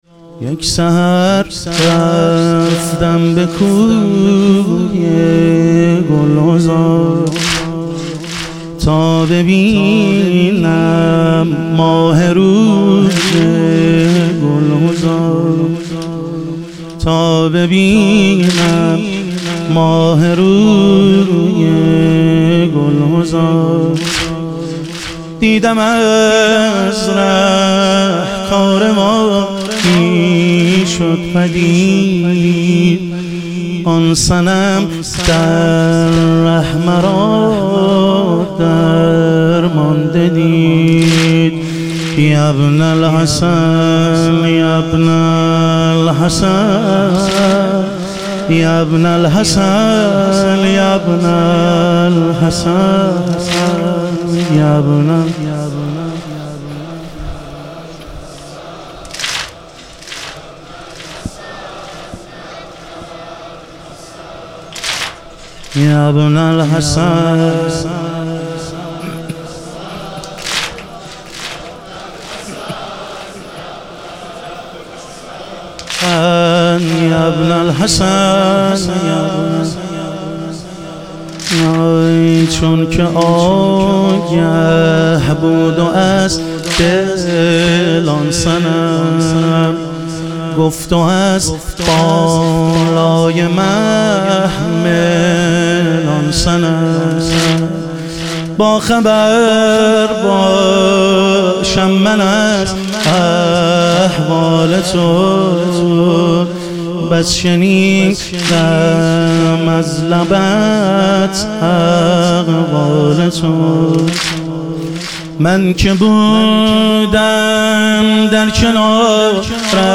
ایام فاطمیه اول - واحد